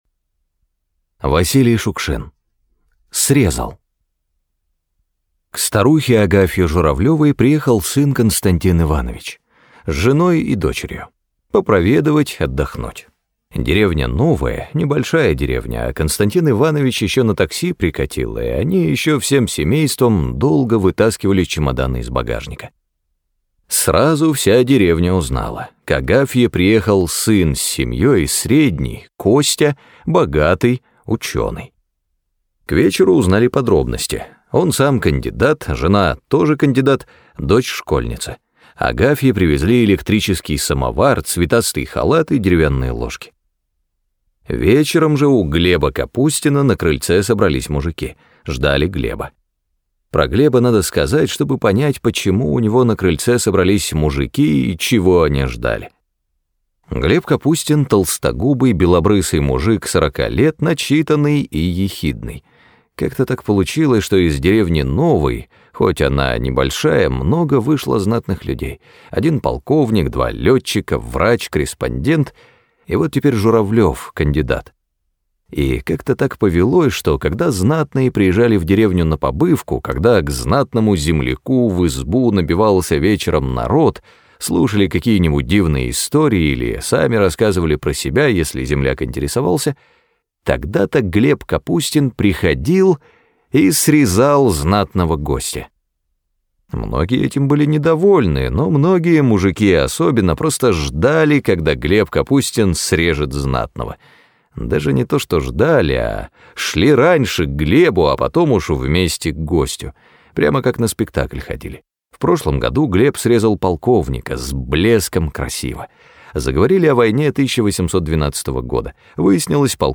Срезал - аудио рассказ Шукшина В.М. Однажды в деревню из города приехал проведать мать и отдохнуть кандидат наук с супругой и дочкой...